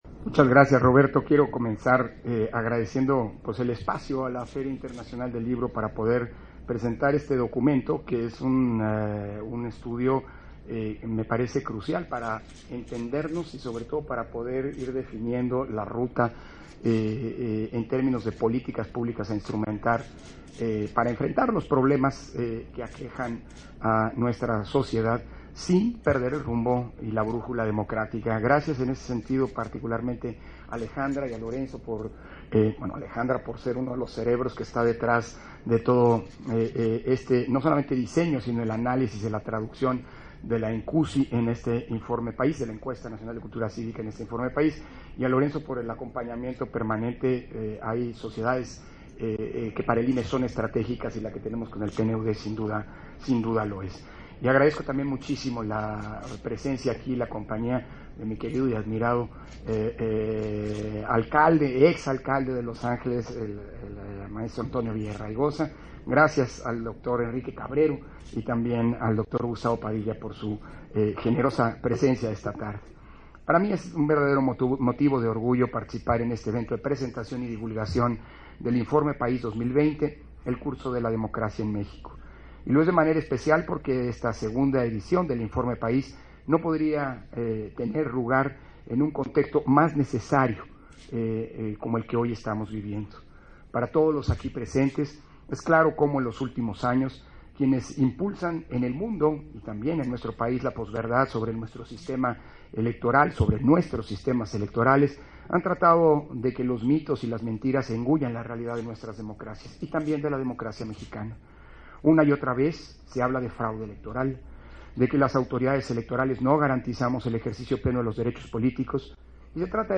271122_AUDIO_INTERVENCIÓN-CONSEJERO-PDTE.-CÓRDOVA-INFORME-PAÍS-2020_-EL-CURSO-DEMOCRACIA-EN-MÉXICO - Central Electoral